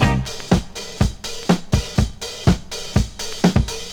• 122 Bpm HQ Drum Loop Sample C Key.wav
Free drum beat - kick tuned to the C note. Loudest frequency: 1586Hz
122-bpm-hq-drum-loop-sample-c-key-EQx.wav